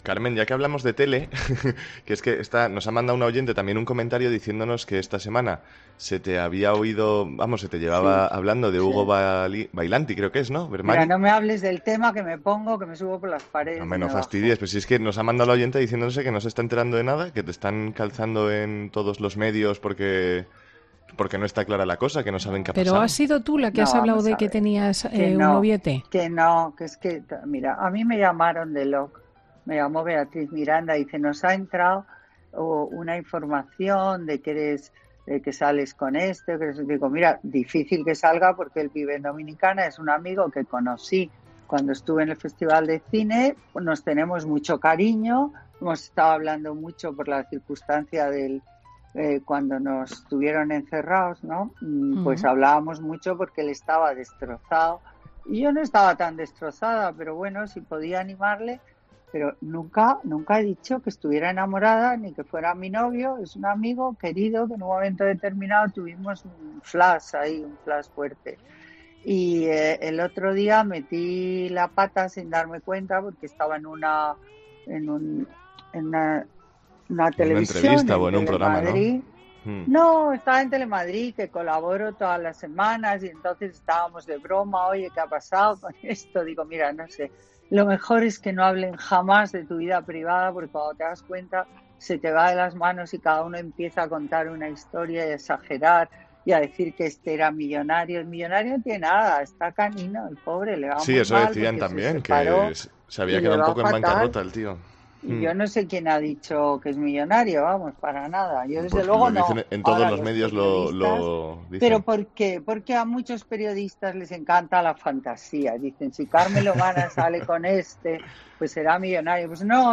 La socialité, Carmen Lomana, revela en COPE si tiene un novio millonario y explica el lapsus que soltó esta semana en Telemadrid